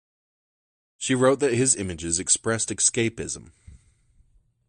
Pronounced as (IPA) /ˈɪmɪd͡ʒɪz/